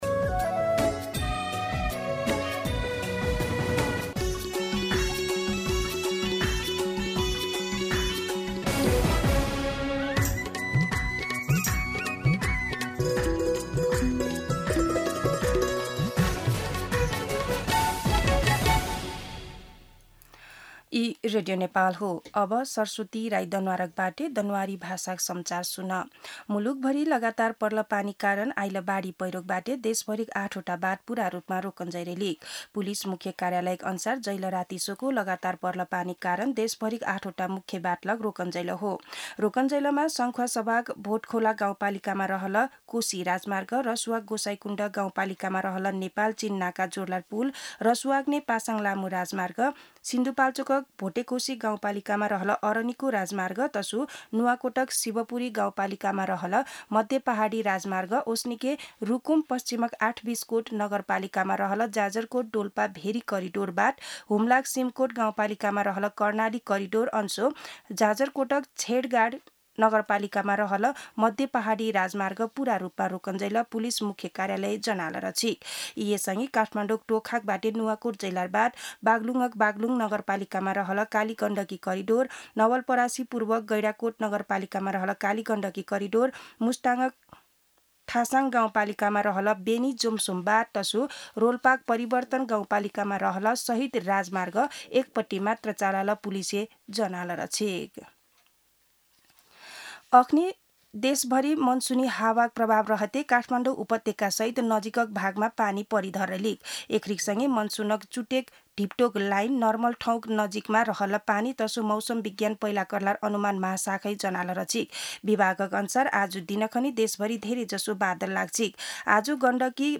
दनुवार भाषामा समाचार : १२ साउन , २०८२
Danuwar-News-4-12.mp3